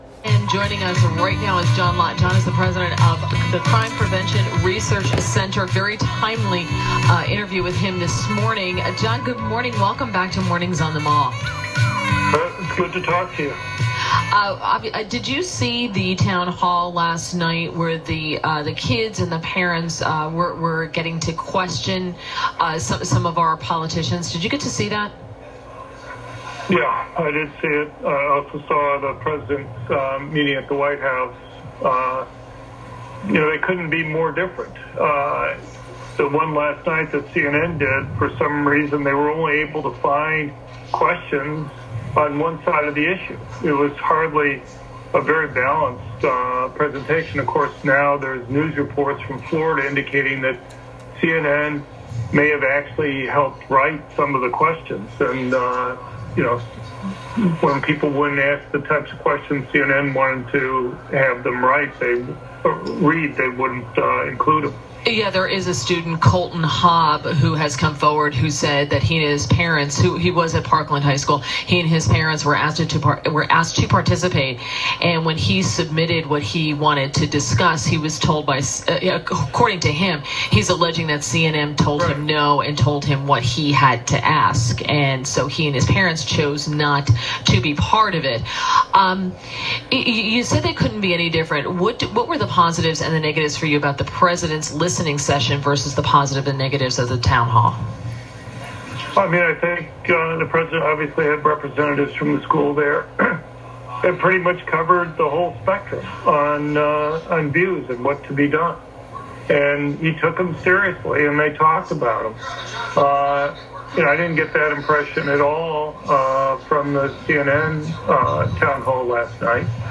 Dr. John Lott joined WMAL’s Morning on the Mall to discuss CNN’s Town Hall on guns.